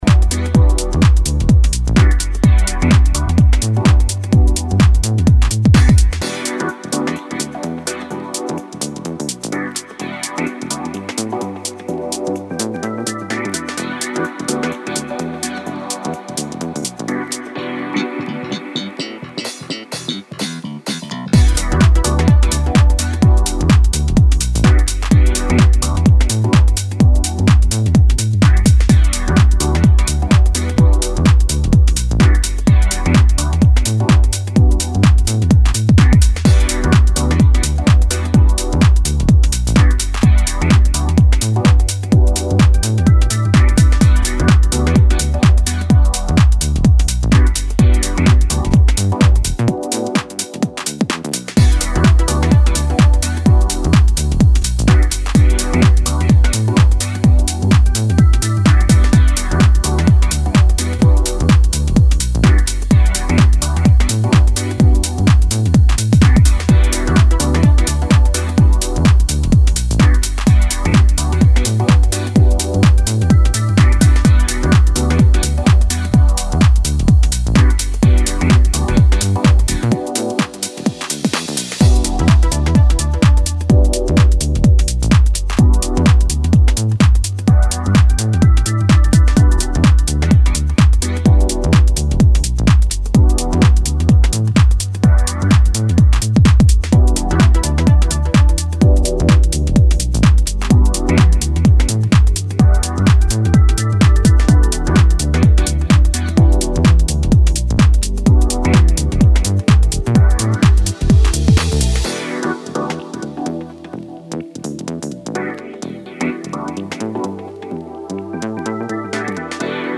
powerful vibes and funky grooves.
house music